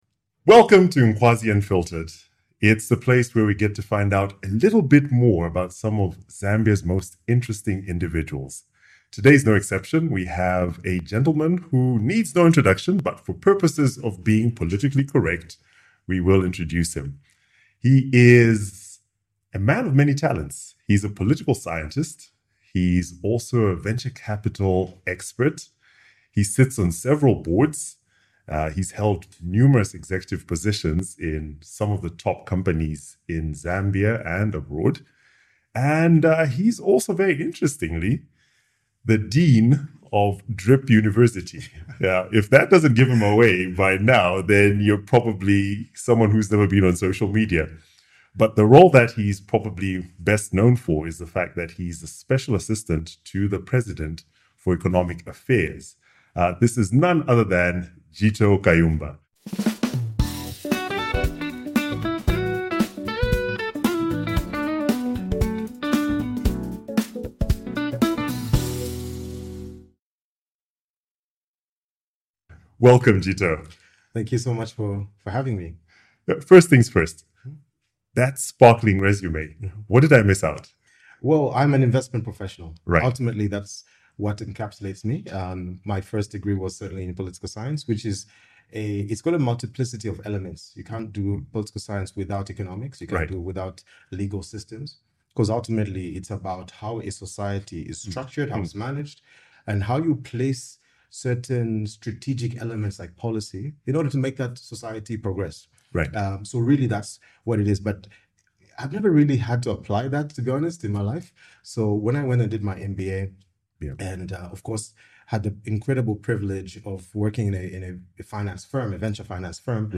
We sit down with Jito Kayumba, Special Assistant to the President for Economic and Development Affairs. From humble beginnings to holding one of the most influential advisory roles in Zambia, Jito shares a journey rooted in discipline, strategy, and service.